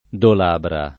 dolabra [ dol # bra ] s. f.